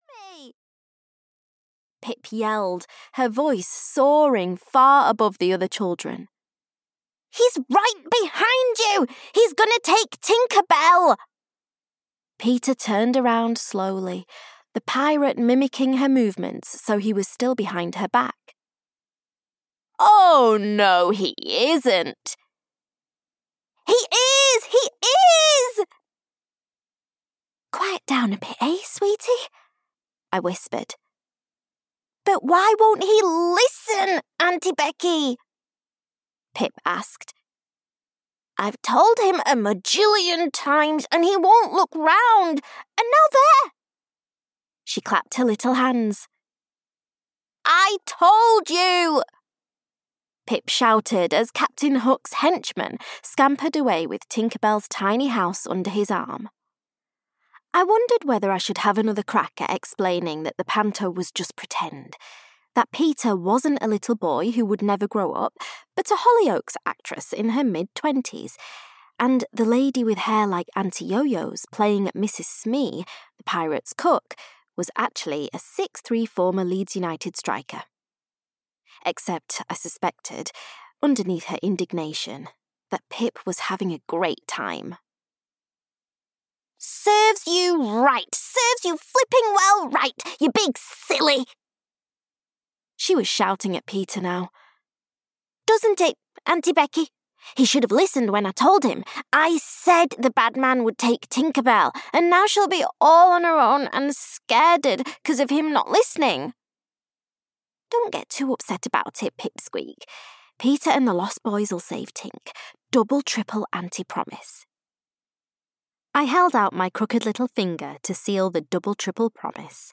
The Perfect Fit (EN) audiokniha
Ukázka z knihy